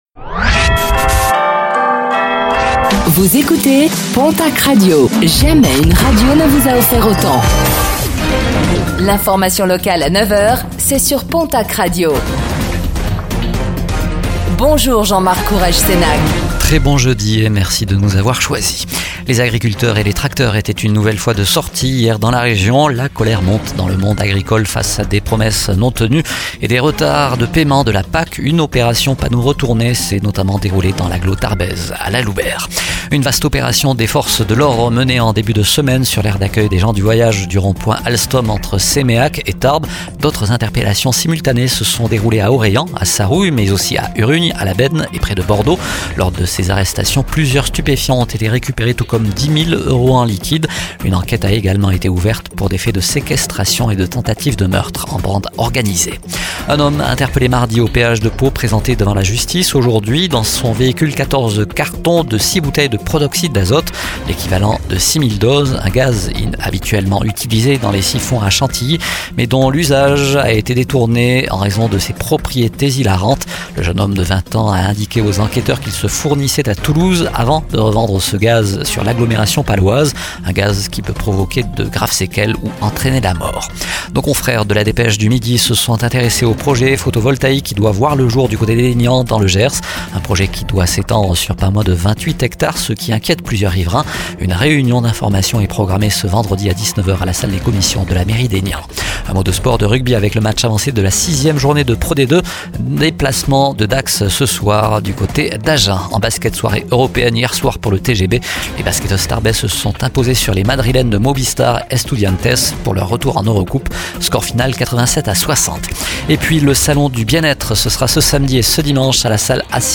Infos | Jeudi 10 octobre 2024